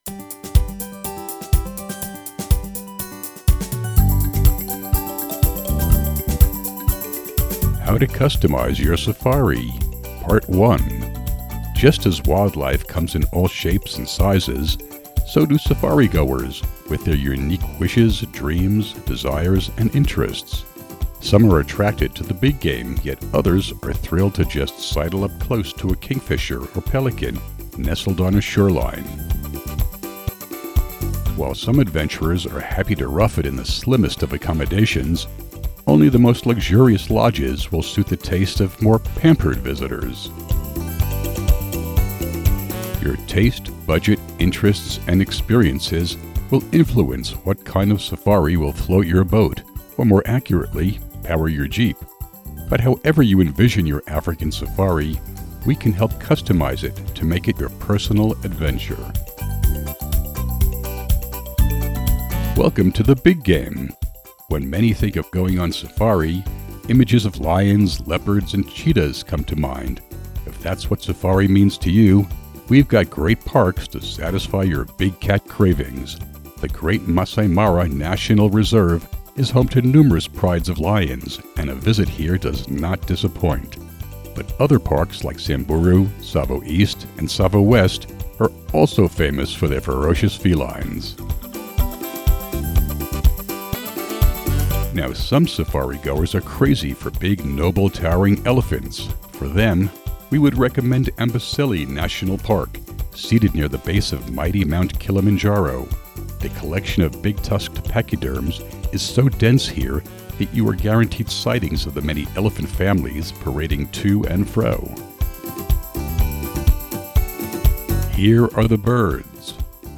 Listen to an audio version of this blog post!